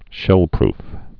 (shĕlprf)